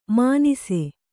♪ mānise